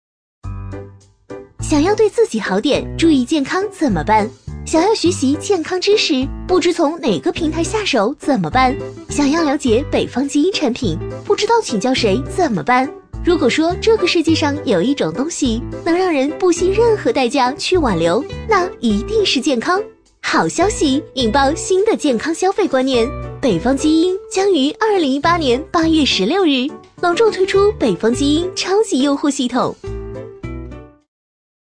A类女50
【女50号MG、飞碟说】-北方基因
【女50号MG、飞碟说】-北方基因.mp3